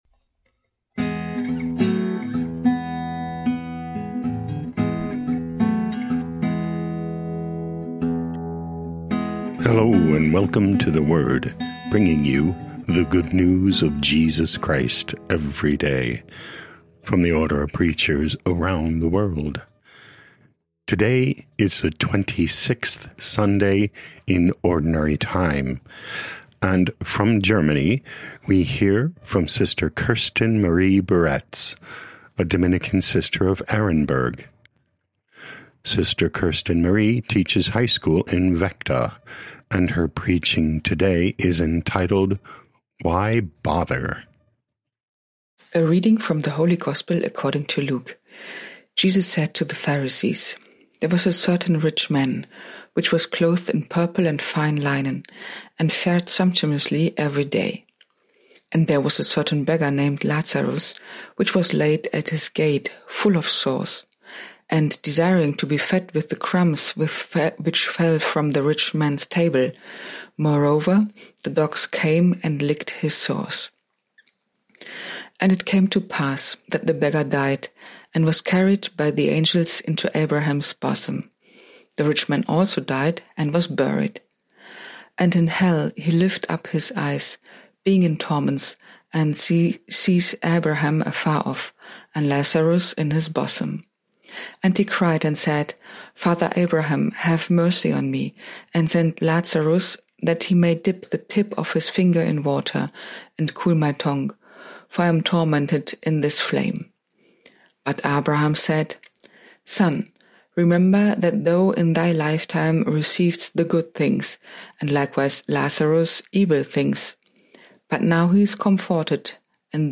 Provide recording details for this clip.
Podcast: Play in new window | Download For 28 September 2025, The Twenty Sixth Sunday in Ordinary Time, based on Luke 16:19–31, sent in from Vechta, Germany.